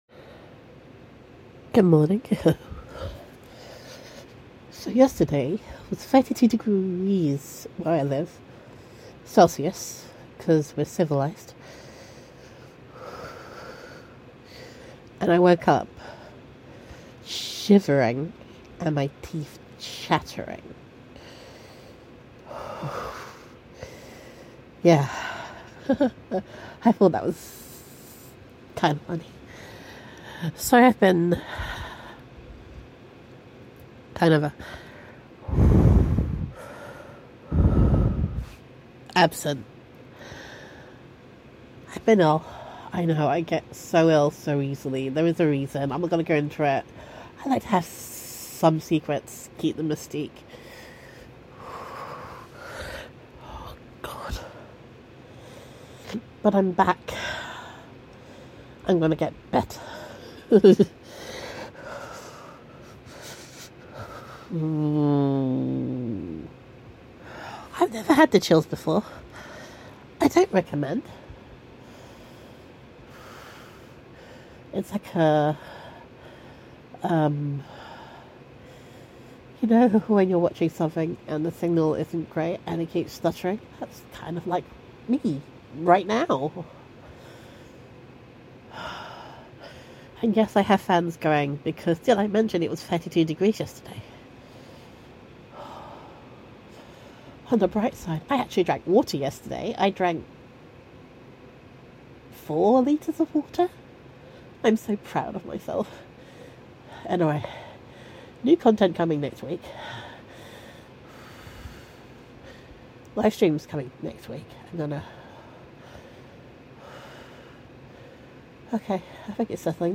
Ps I love the lisp, it is awesome.